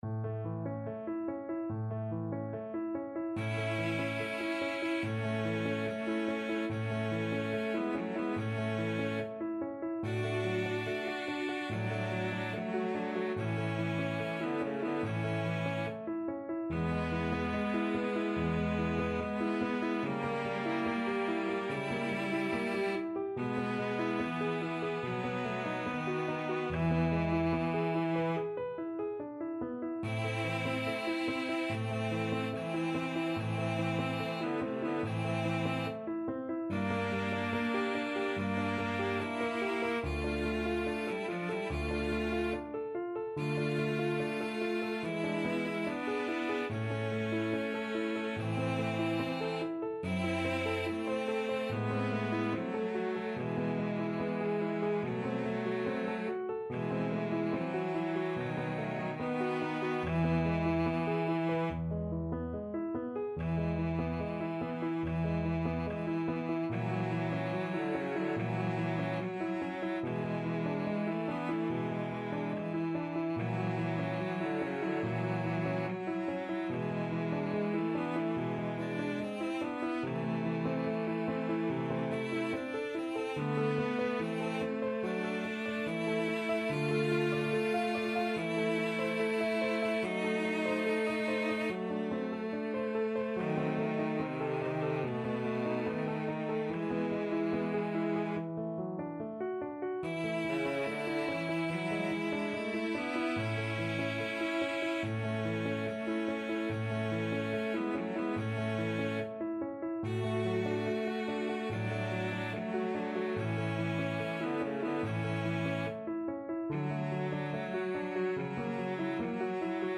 Andante =72
4/4 (View more 4/4 Music)
Cello Duet  (View more Intermediate Cello Duet Music)
Classical (View more Classical Cello Duet Music)